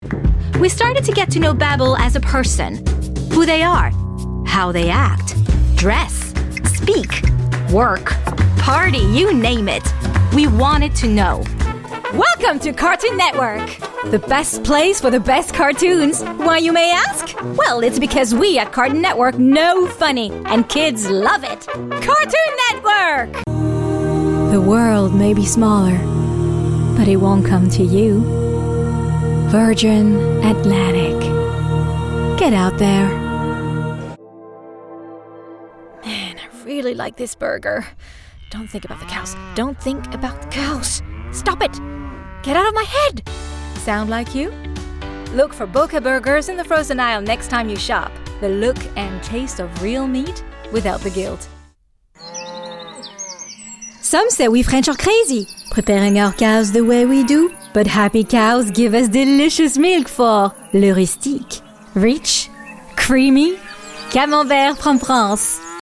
Commercial Showreel
Female
American Standard
Bright
Friendly
Confident
Warm